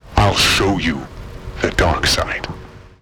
しかし旧型のサウンドフォントでは３つも音声案内がありました。